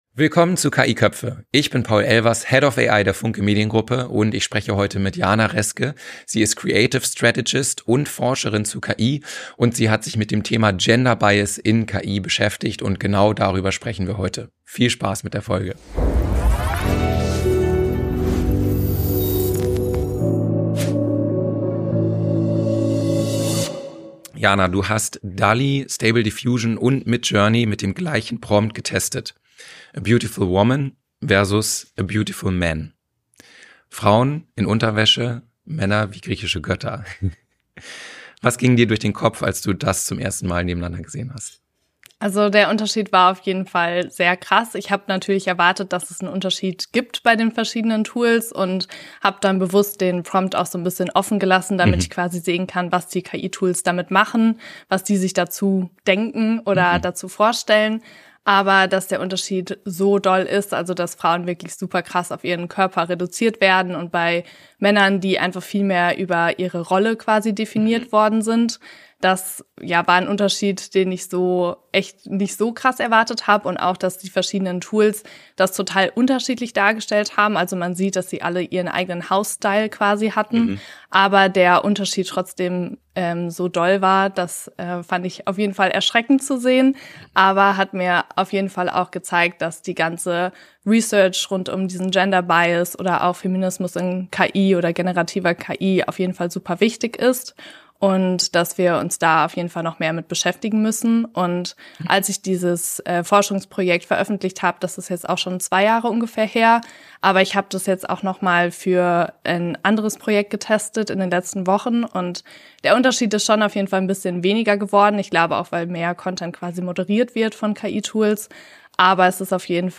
Ein Gespräch über Trainingsdaten, Medienkompetenz und die Frage, wie authentisch KI-generierte Inhalte überhaupt noch sein können.